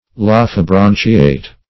Search Result for " lophobranchiate" : The Collaborative International Dictionary of English v.0.48: Lophobranchiate \Loph`o*bran"chi*ate\, a. (Zool.) Of or pertaining to the Lophobranchii .
lophobranchiate.mp3